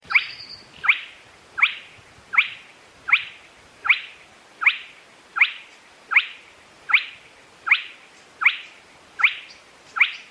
polluela-pintoja.mp3